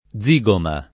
zigoma [ +z&g oma ] → zigomo